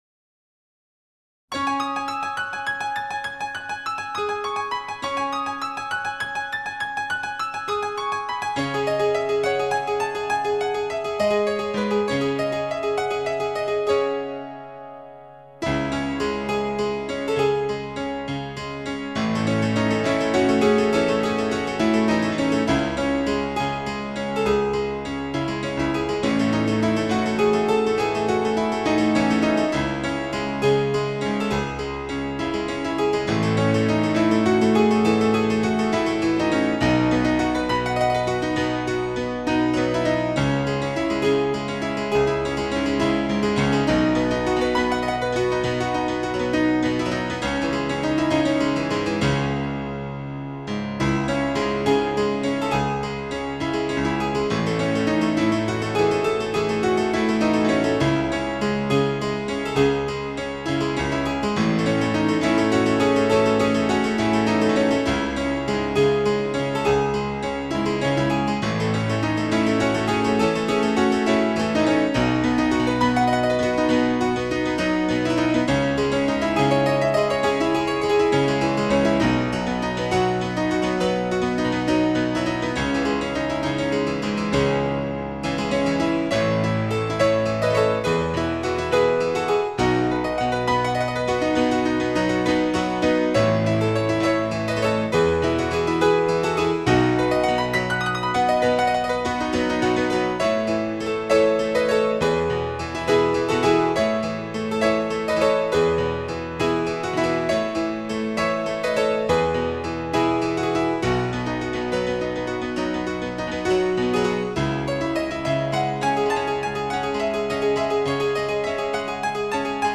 [純鋼琴音樂]
僅低音質壓縮 , 供此線上試聽
充滿寧靜 喜悅 生命力